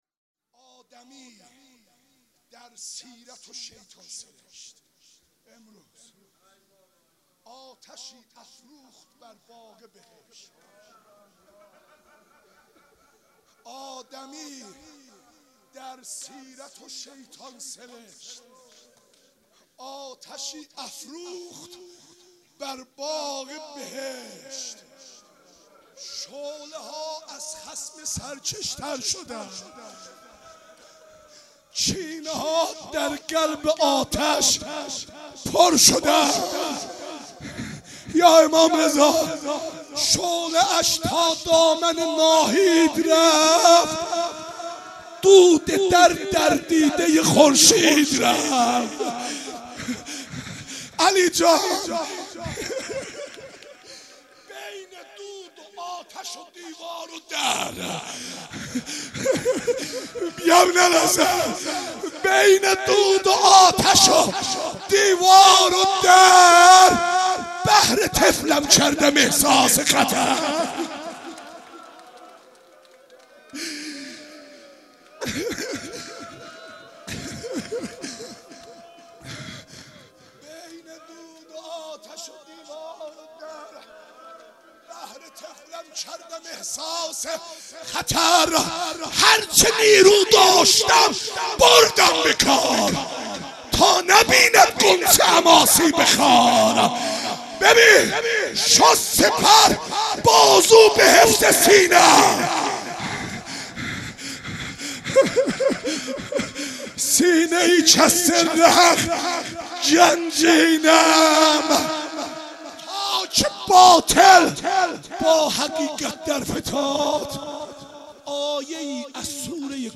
مشهد الرضا - مدح و رجز - 12 - 1395